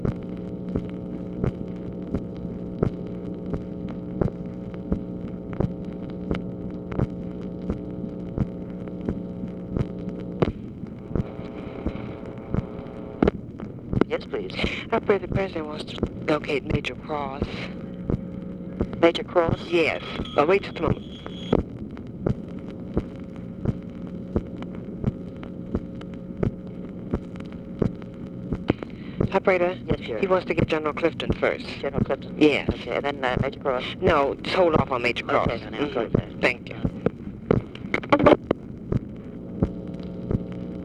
Conversation with TELEPHONE OPERATOR